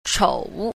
10. 瞅 – chǒu – thiễu, thu (dòm, ngó)